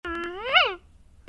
Sound Buttons: Sound Buttons View : Boy Moan 2